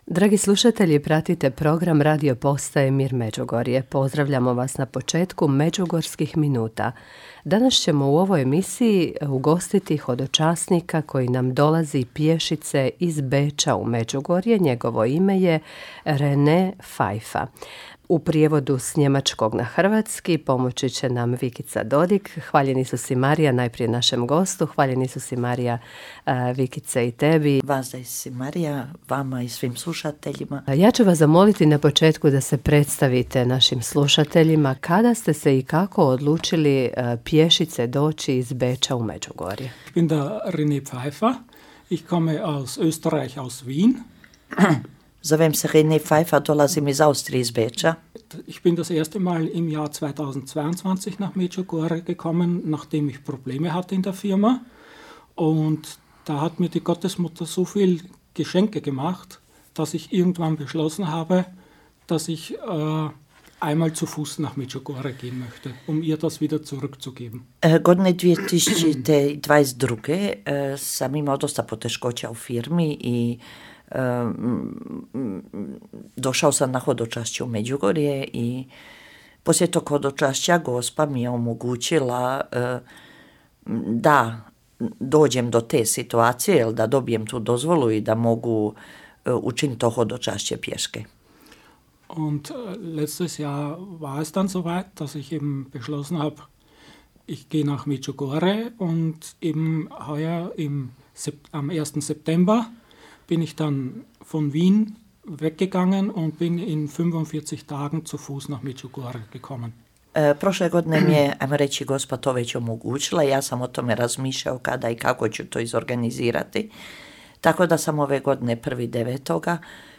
Na radiju smo neke od njih ugostili i zabilježili njihova svjedočanstva.